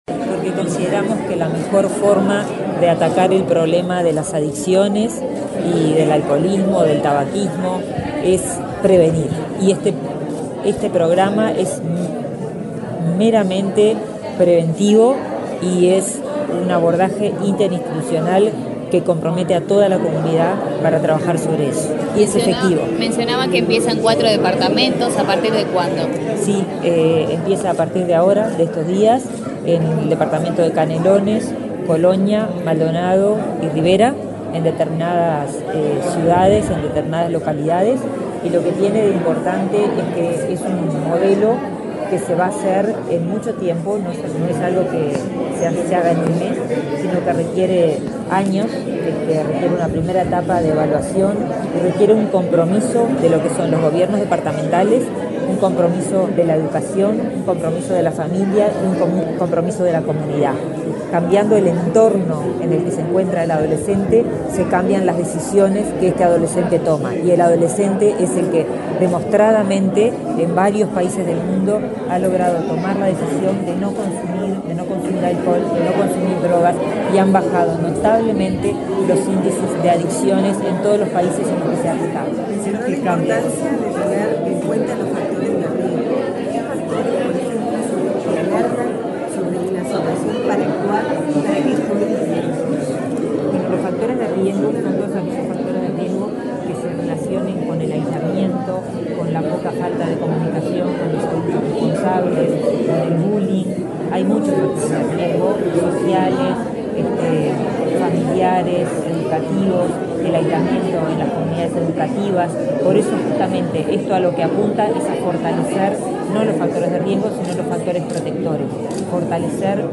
Declaraciones de la ministra de Salud Pública, Karina Rando
Declaraciones de la ministra de Salud Pública, Karina Rando 04/11/2024 Compartir Facebook X Copiar enlace WhatsApp LinkedIn Este lunes 4 en el Ministerio de Salud Pública (MSP), se realizó la presentación y el lanzamiento del programa de prevención de consumo problemático de drogas Conciencia y Acción Colectiva. Luego del acto, la titular de la cartera, Karina Rando, dialogó con la prensa.